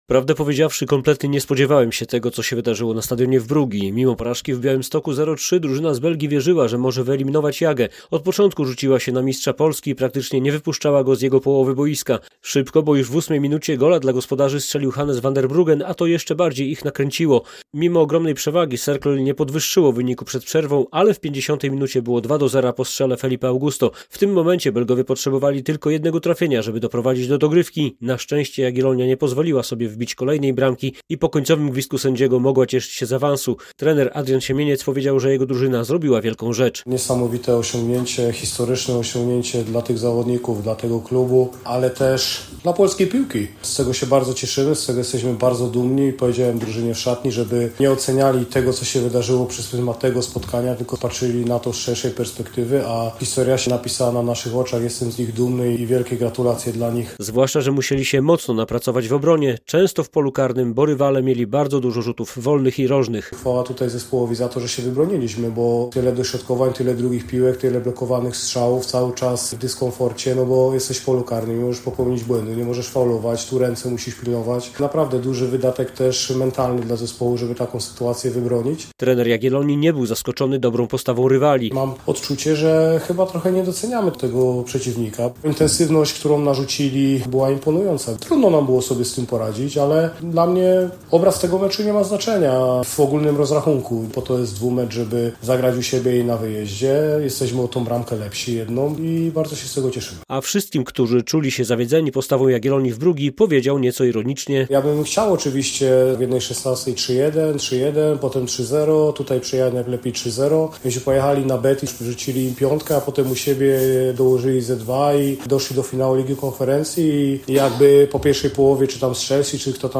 Po awansie Jagiellonii do ćwierćfinału Ligi Konferencji szkoleniowiec białostoczan Adrian Siemieniec podkreślił, że to ogromne osiągnięcie nie tylko klubu, ale i polskiej piłki nożnej. - Historia napisała się dzisiaj na naszych oczach - mówił po meczu w Brugii.
Jagiellonia w ćwierćfinale Ligi Konferencji - relacja